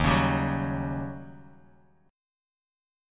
D#7#9 chord